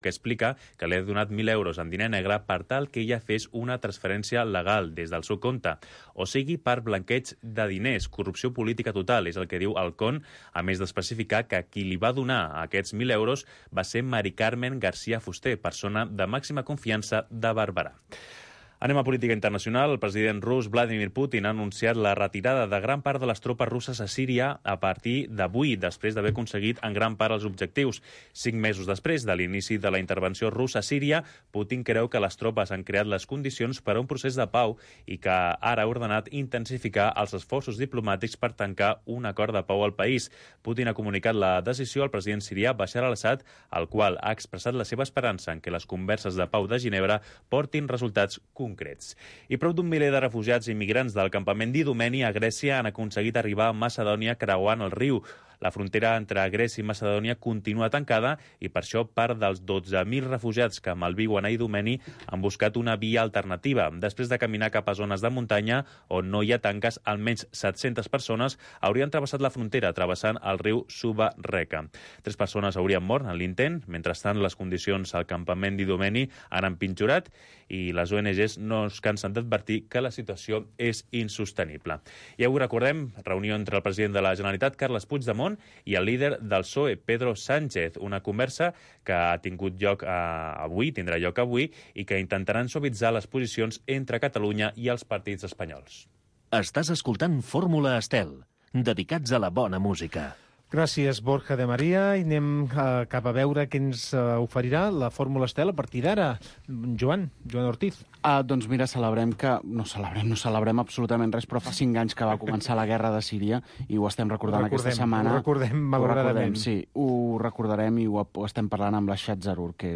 ens presenta un pacient que ens explica el seu cas en primera persona.